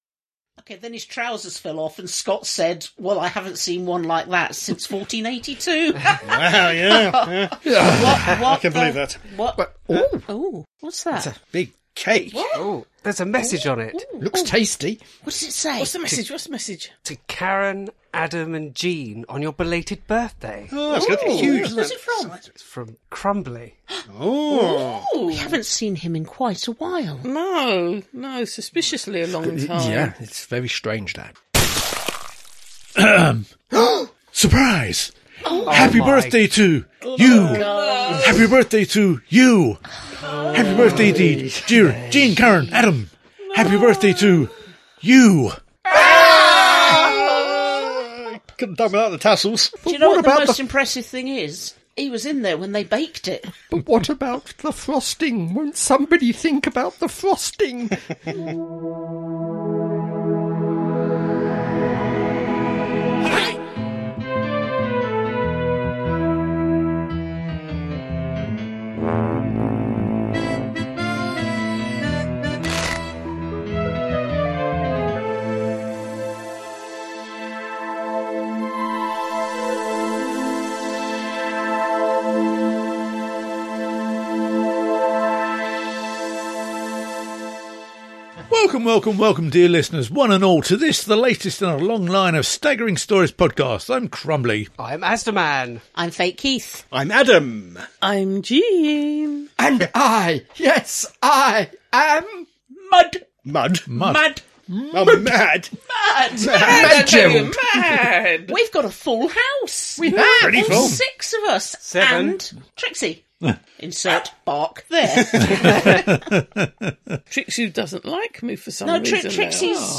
00:00 – Intro and theme tune.
68:44 — End theme, disclaimer, copyright, etc.